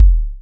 Bassdrum-06.wav